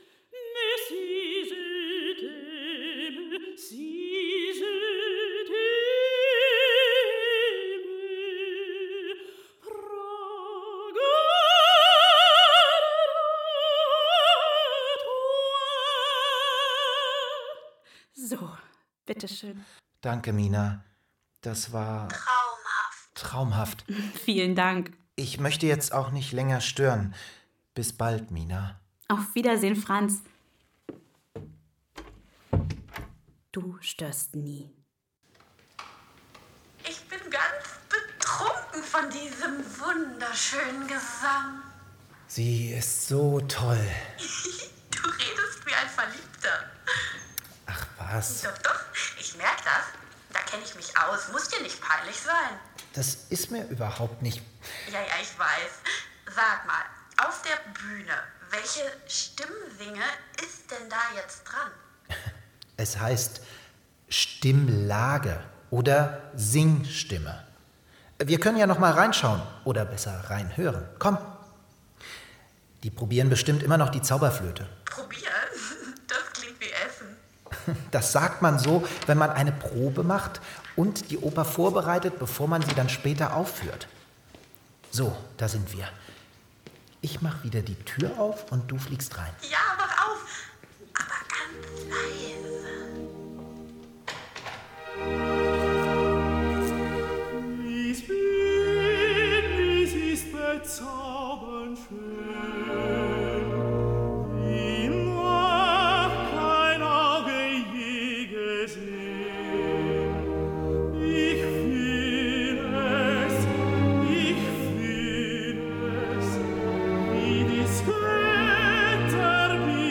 13 Opern-Hörspiele mit ausführlichem Booklet